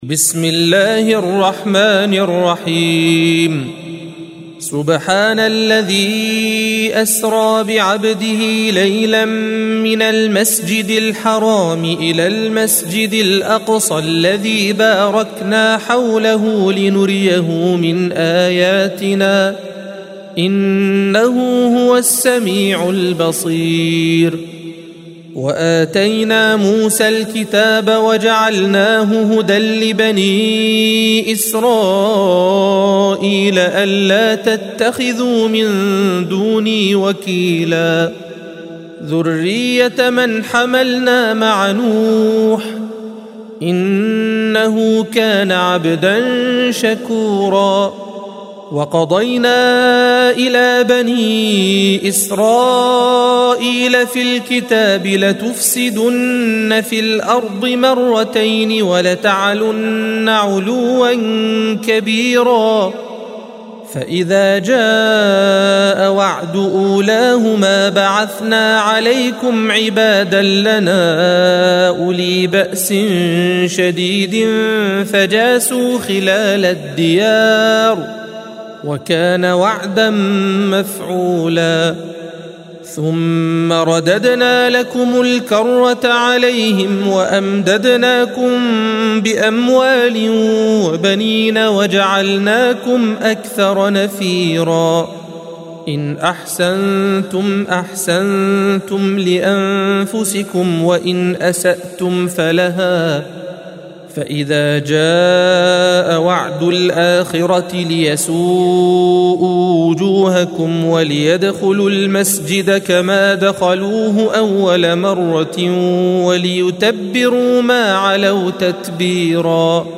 دار السيدة رقية (ع) للقرآن الكريم / الصوتيات